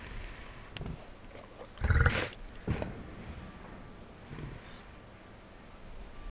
Pig_Snort_SlowMo_SFX.wav